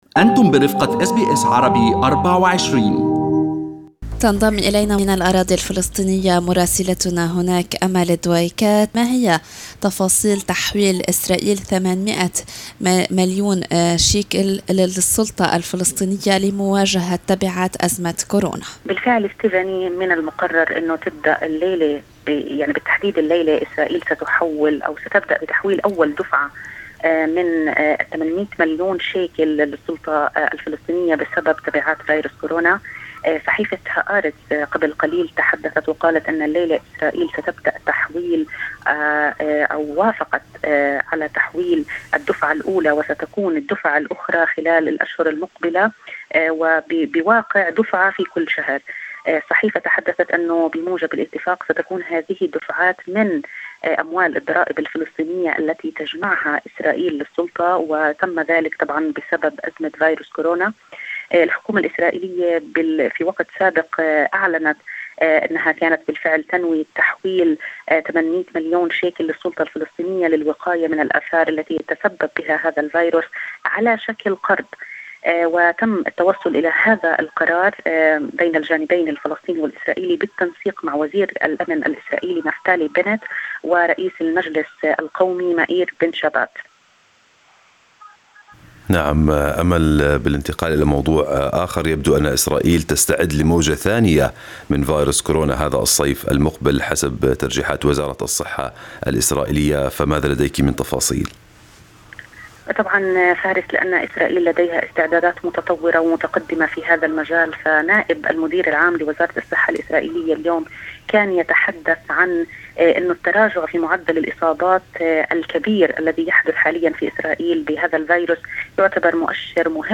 من مراسلينا: أخبار الأراضي الفلسطينية في أسبوع 11/5/2020